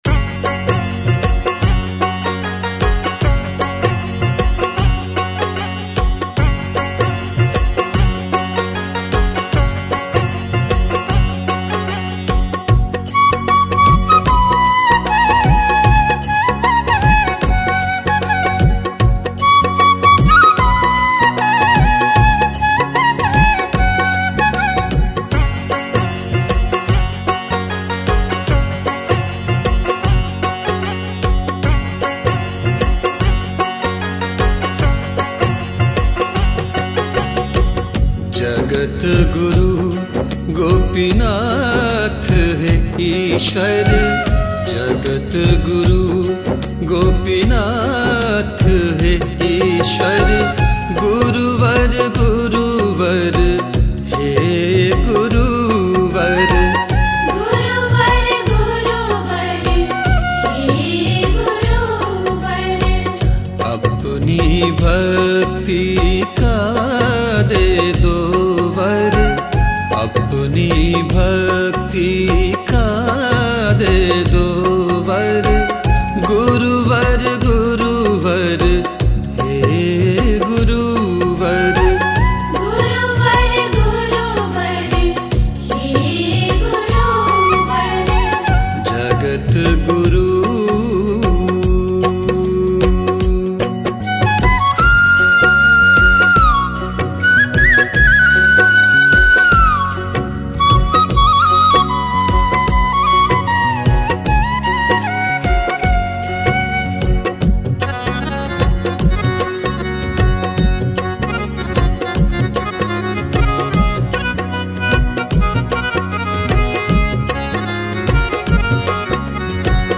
Bhajan 3